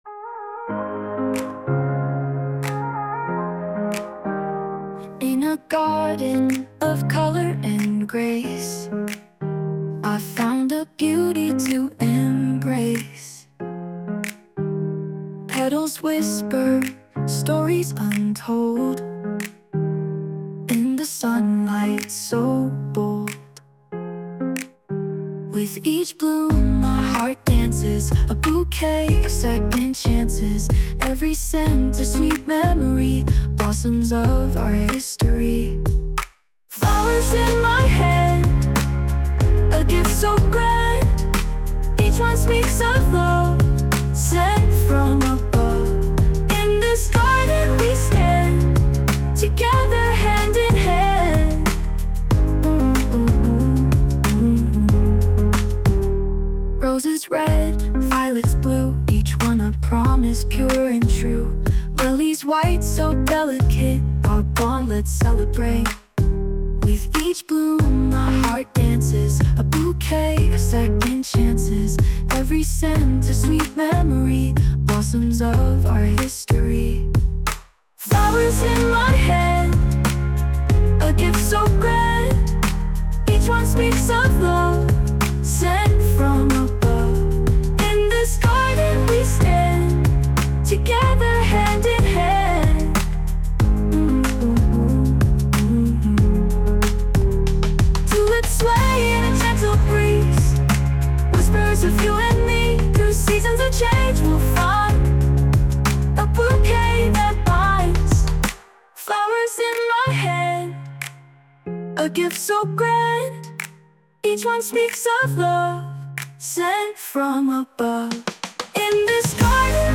洋楽女性ボーカル著作権フリーBGM ボーカル
著作権フリーBGMです。
女性ボーカル（洋楽）曲です。
「花束」をテーマに優しい曲をイメージして制作しました✨